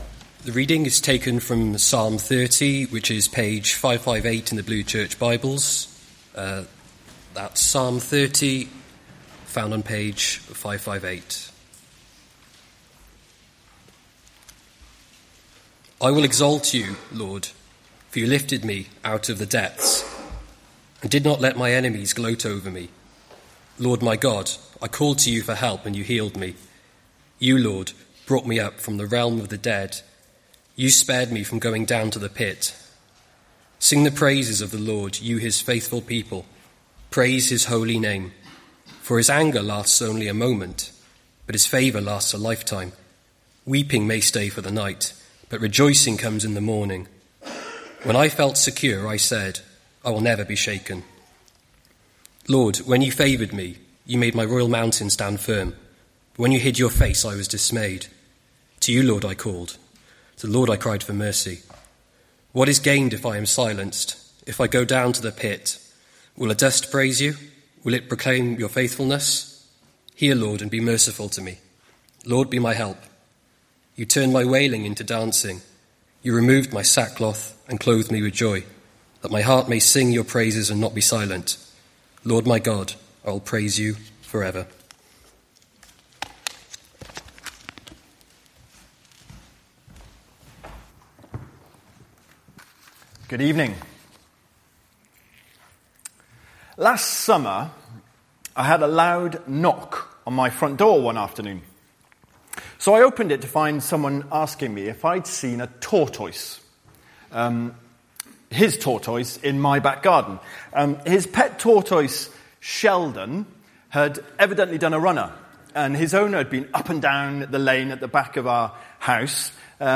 Evening Service
Prayer and Praise: Thanksgiving Psalm 30 Sermon